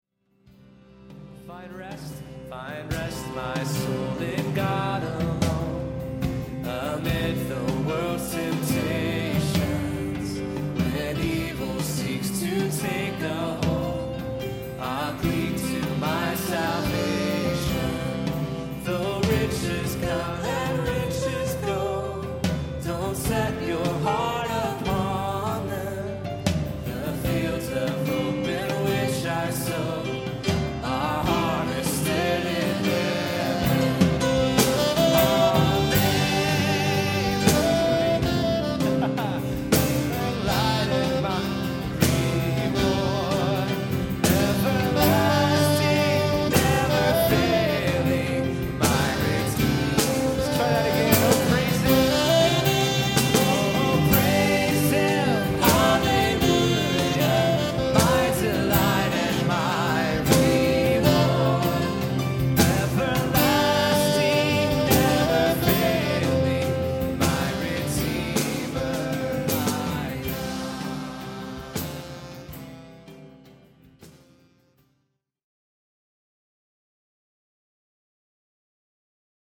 A few Saturdays ago we began our Saturday service with the Stuart Townend/Keith Getty song “Creation Sings the Father’s Song”.
Both songs are strong, congregational, truth-filled songs.
They also sound a lot alike.
So after the second verse of “My Soul Finds Rest” I was so caught up in the moment that I launched into the chorus of “Creation Sings”.
2. I would hardly called that messing up royally, sure you might have confused some people a little but you carried right on and just repeated the chorus, no big deal really.